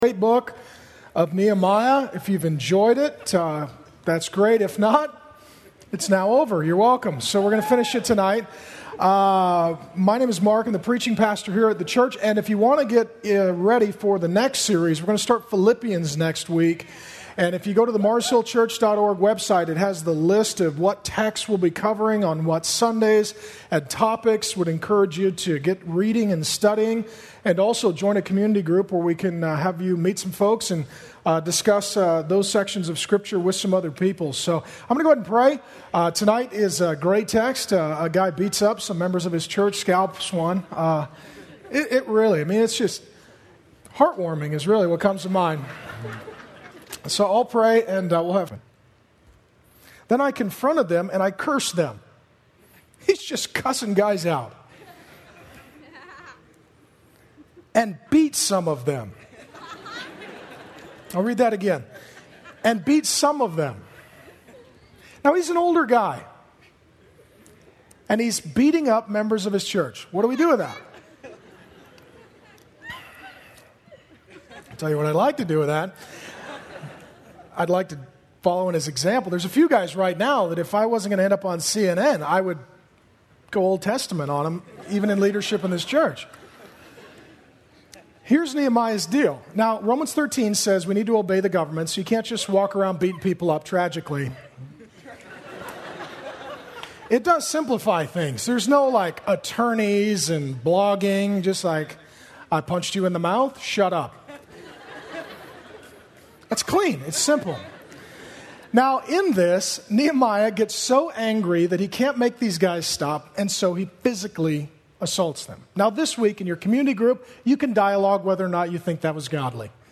AUDIO LINK to full final Nehemiah sermon, “Fathers & Fighting,” preached by Mark Driscoll that night.
AUDIO LINK to shortened clip with highlights of the “Fathers & Fighting” sermon.